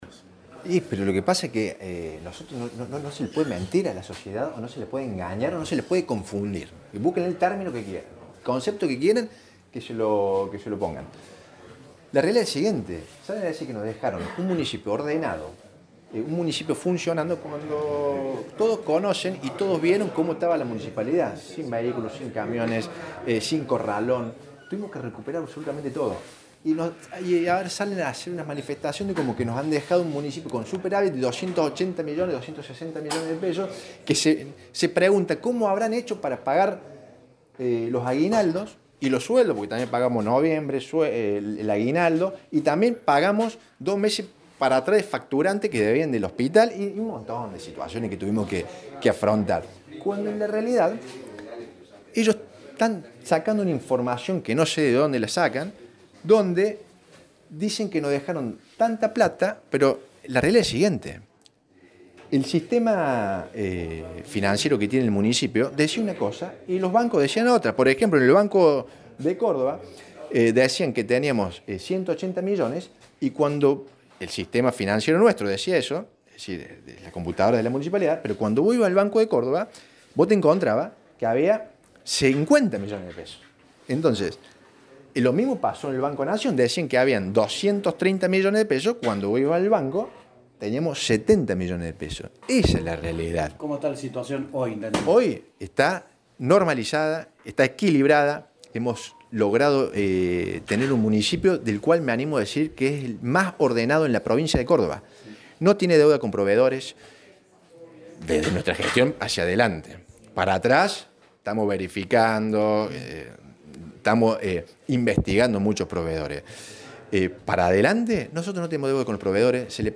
El intendente de Villa Nueva, Ignacio Tagni, ofreció una conferencia de prensa a periodistas, con el objetivo de brindar información sobre la situación financiera y económica del municipio.